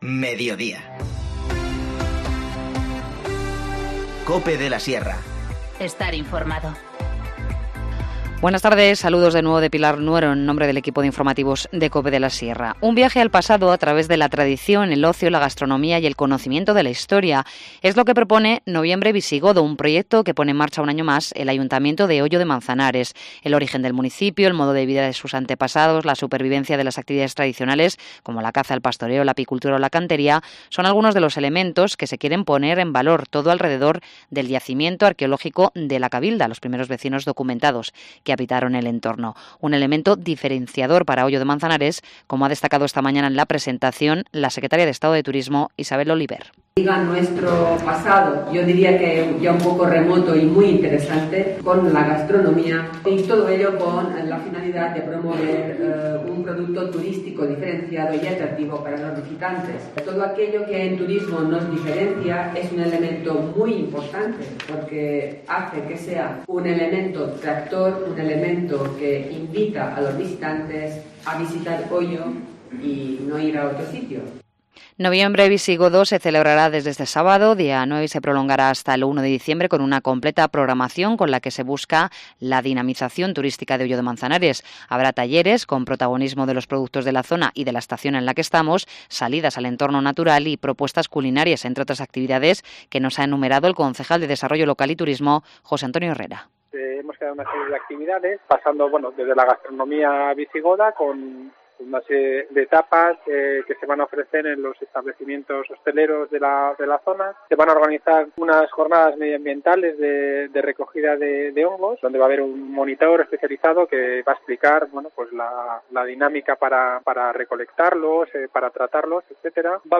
Informativo Mediodía 6 noviembre 14:50h